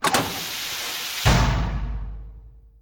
DoorClose2.ogg